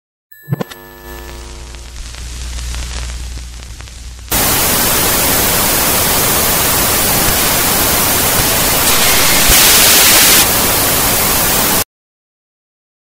Звуки телевизора
На этой странице собраны разнообразные звуки телевизора: от характерного писка при включении до статичных помех и переключения каналов.